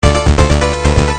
聴き比べてみれば分かりますけど音色が全然違うんですって！
このBGM・SEが使用されているタイトルをお答えください。